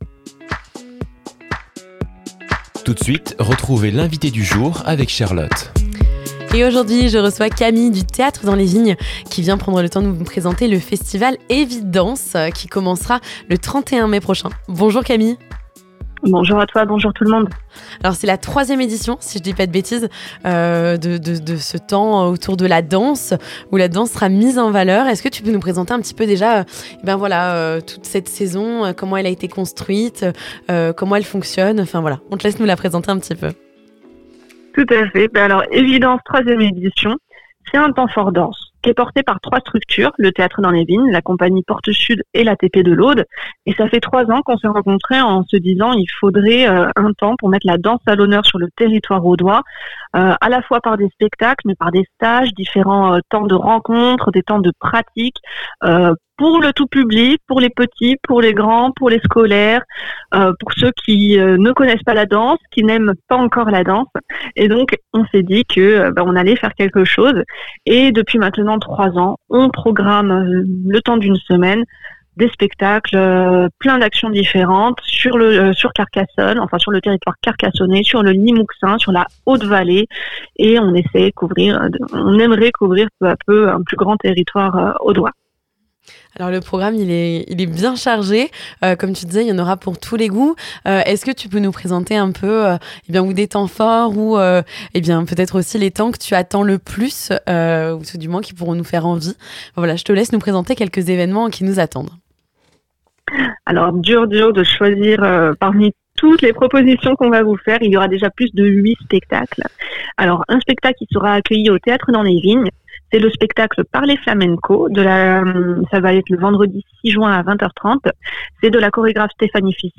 INTERVIEW - La 3ème édition de Evidanse - Radio Marseillette